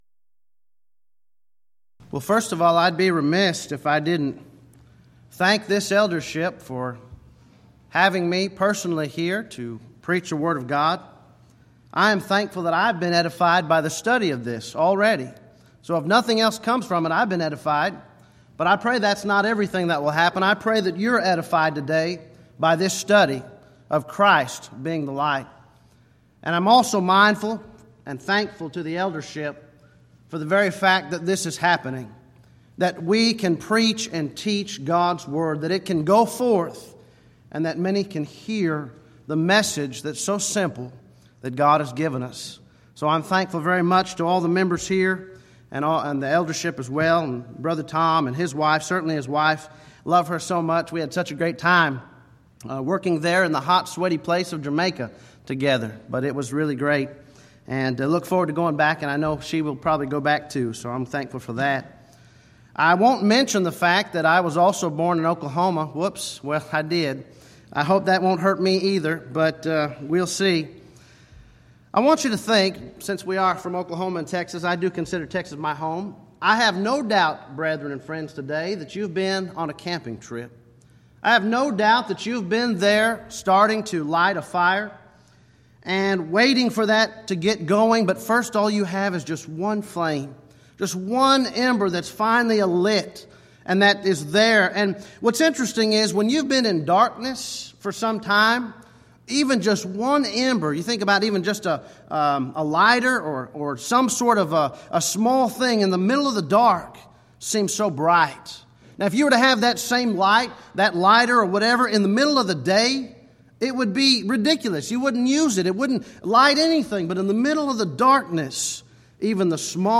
Event: 4th Annual Back to the Bible Lectures Theme/Title: The I Am's of Jesus